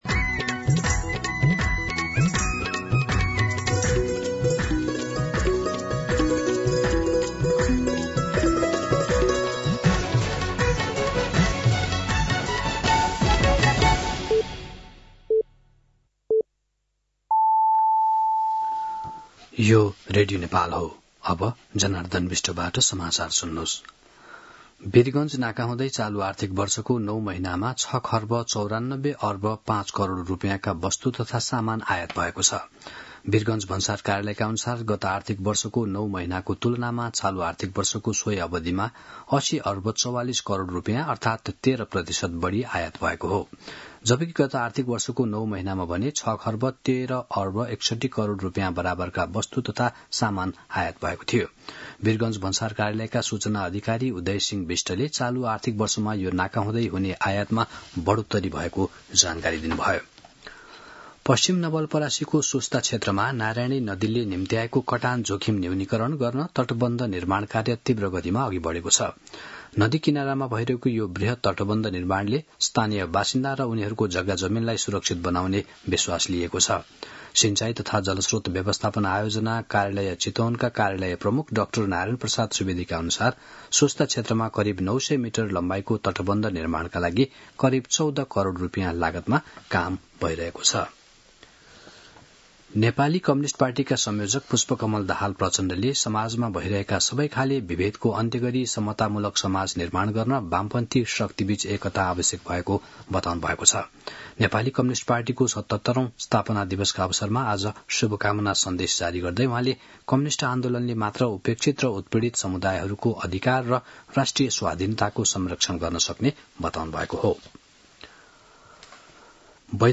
दिउँसो १ बजेको नेपाली समाचार : ९ वैशाख , २०८३
1pm-News-09.mp3